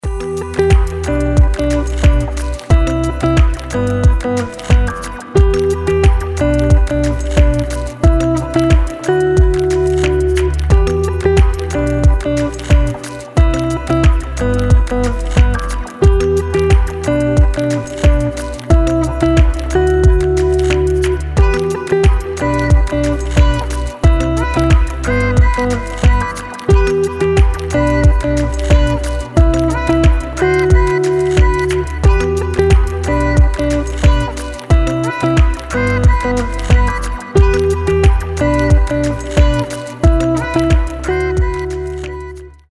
гитара
deep house
мелодичные
Electronic
спокойные
забавный голос
теплые
Melodic house
Chill